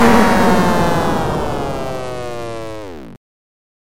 8位爆炸 " SFX爆炸08
描述：复古视频游戏8位爆炸
标签： 复古 爆炸 8位 视频游戏
声道立体声